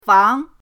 fang2.mp3